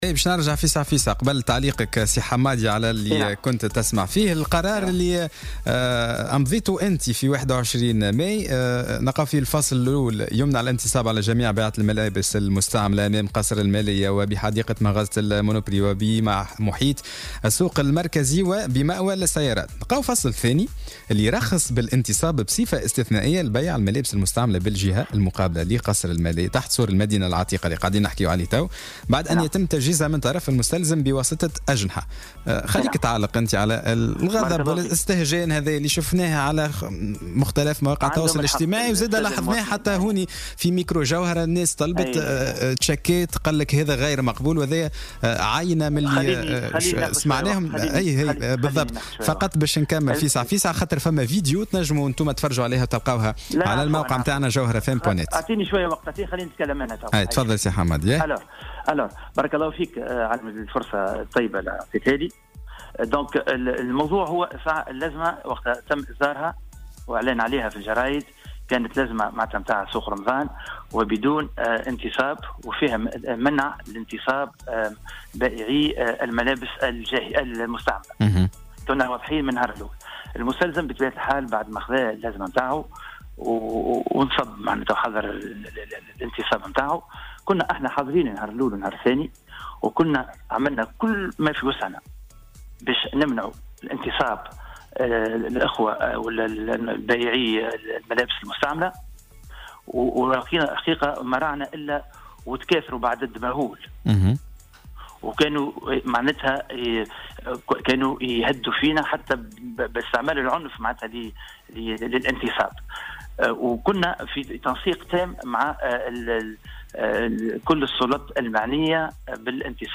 وأضاف في مداخلة له اليوم في برنامج "صباح الورد" على "الجوهرة أف أم" أنه تم منع الانتصاب في المكان المذكور، لكن عددا كبيرا من الباعة لم يمتثلوا لهذا القرار، وهو ما جعلهم يسمحون لهم بذلك بصفة استثنائية شريطة أن تكون العملية منظمة وبشكل لائق.